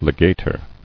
[le·ga·tor]